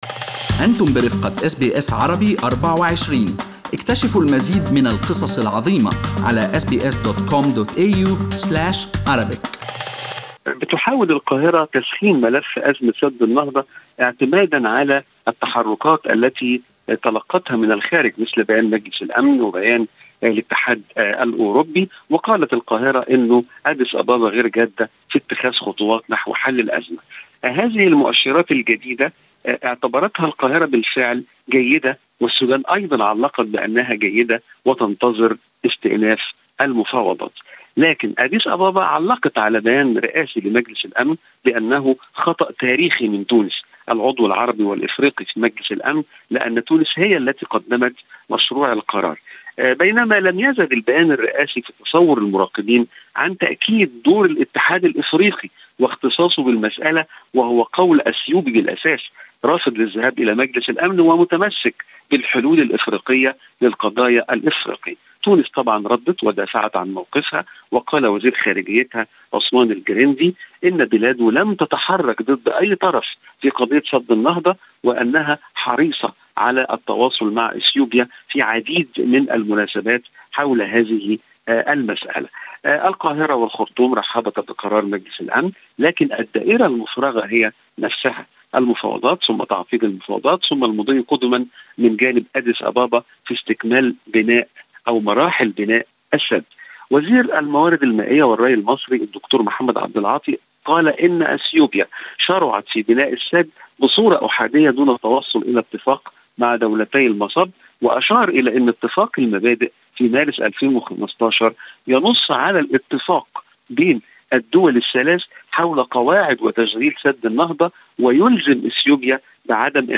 يمكنكم الاستماع إلى تقرير مراسلنا في القاهرة بالضغط على التسجيل الصوتي أعلاه.